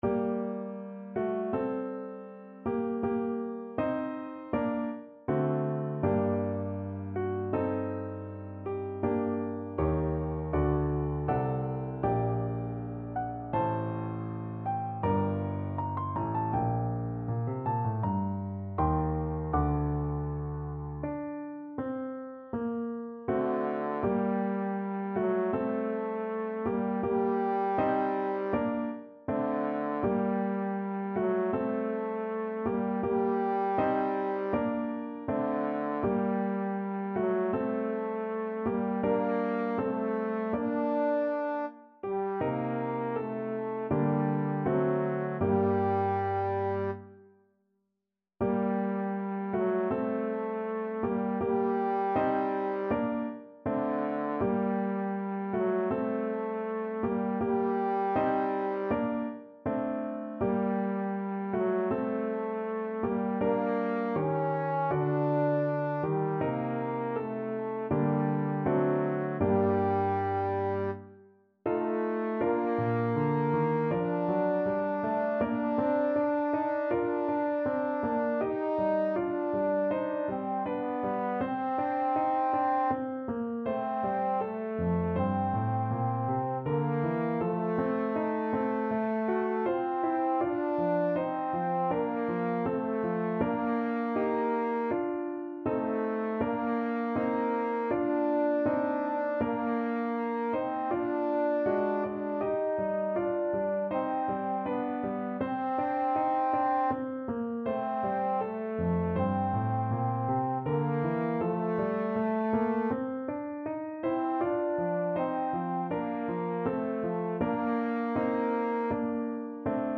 Trombone version
Classical Trombone
Time Signature: 4/4
Tempo Marking: Andante =c.80
Key: G minor